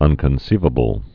(ŭnkən-sēvə-bəl)